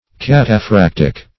Search Result for " cataphractic" : The Collaborative International Dictionary of English v.0.48: Cataphractic \Cat`a*phrac"tic\, a. Of, pertaining to, or resembling, a cataphract.